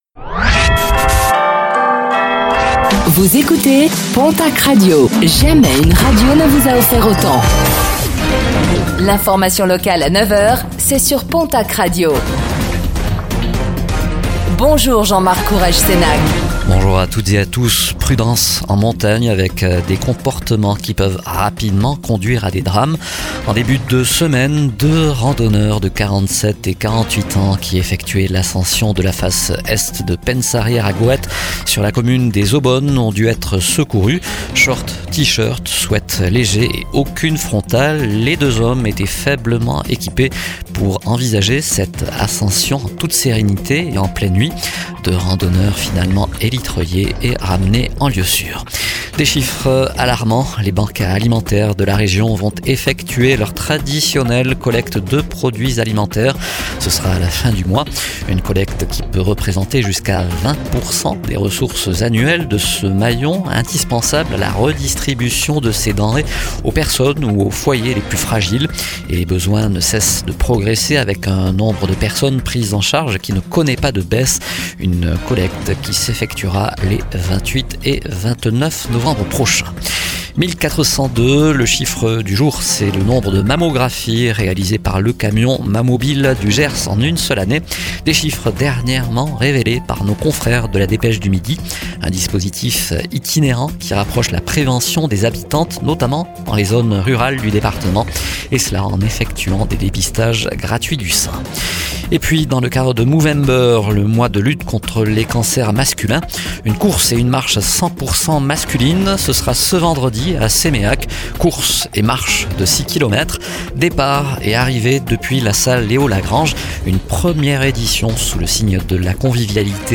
Réécoutez le flash d'information locale de ce jeudi 13 novembre 2025 , présenté par